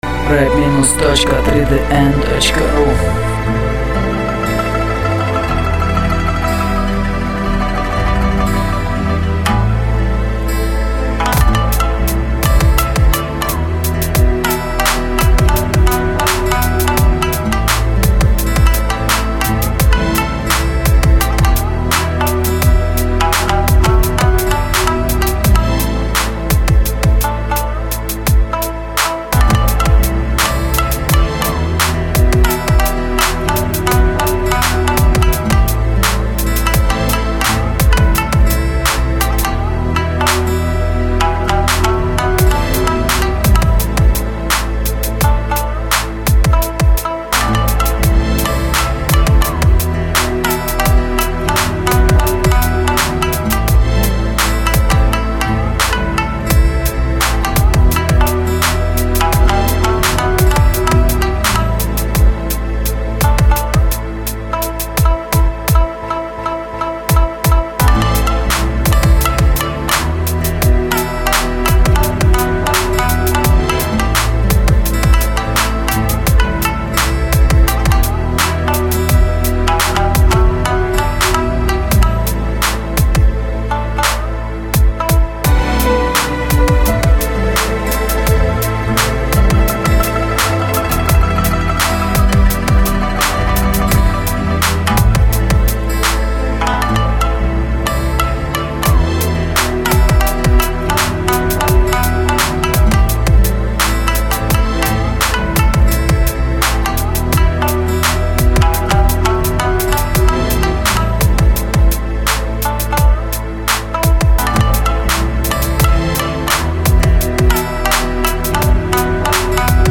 лирический рэп минус, с японским мотивом
Каналы : 2 (стерео)
голос только в начале, легко вырезать